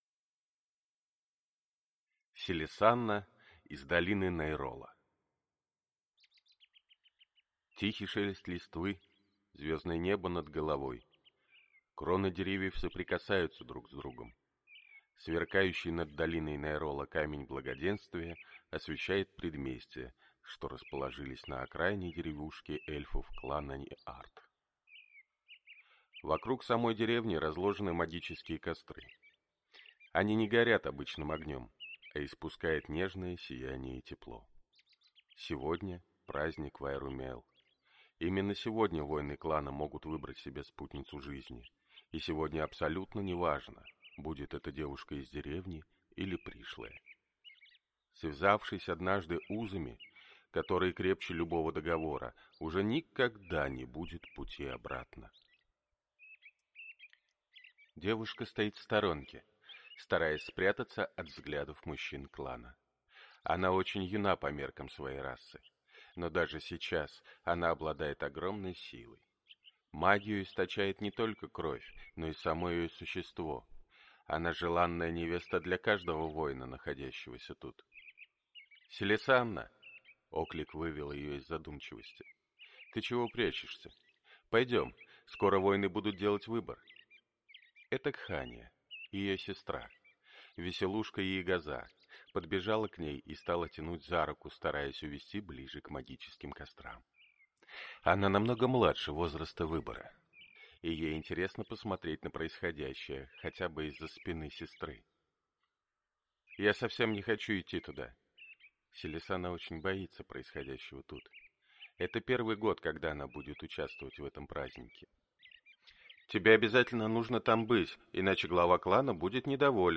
Aудиокнига Селессана из долины Найрола